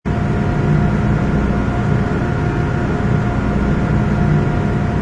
ambience_comm_ground.wav